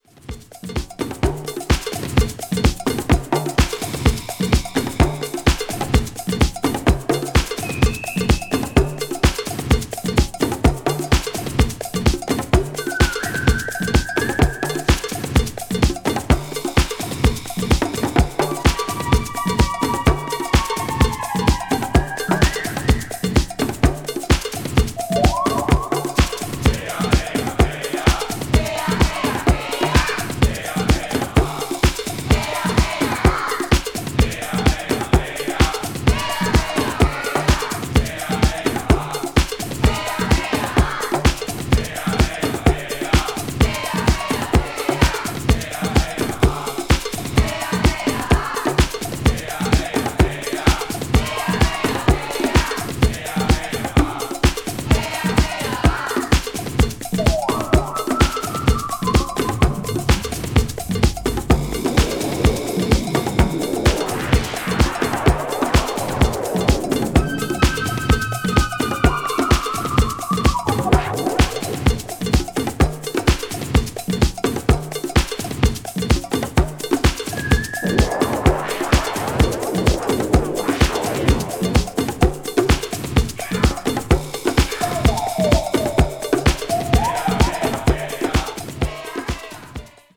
media : EX/EX(わずかにチリノイズが入る箇所あり)
electro   electronic   hi-nrg   synth disco   synthesizer